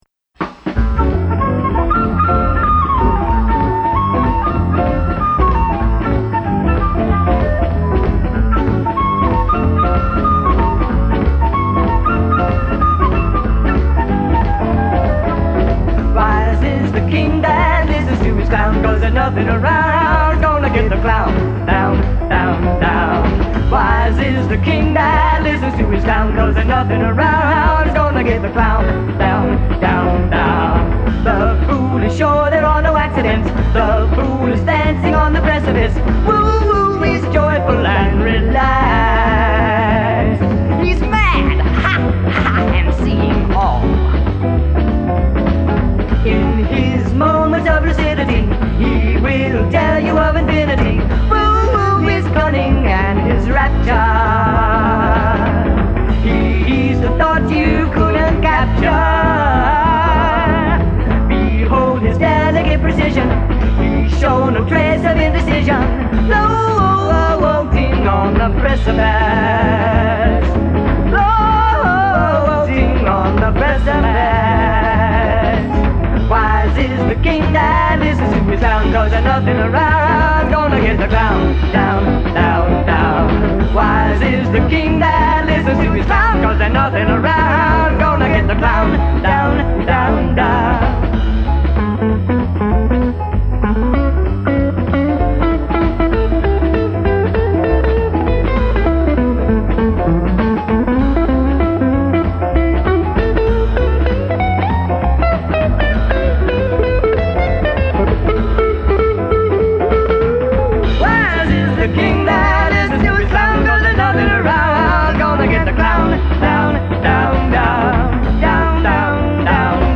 recorded circa 1975 by The Infamous Joy Urchins
love the energy, vocal harmonies, and the swingin' music....excuse me